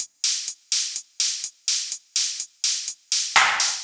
Index of /musicradar/retro-house-samples/Drum Loops
Beat 13 No Kick (125BPM).wav